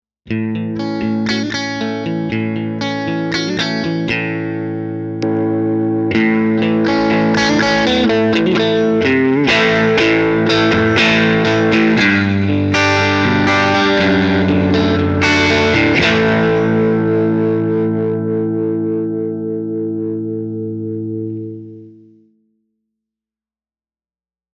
Tutte le clip audio sono state registrate con amplificatori reali iniziando con Ignition spento nei primi secondi per poi accenderlo fino alla fine della clip.
Chitarra: Fender Telecaster (pickup manico e ponte)
Amplificatore combo: Fender Deluxe Reverb con cono Jensen C12K, canale Normal, volume a circa 3/10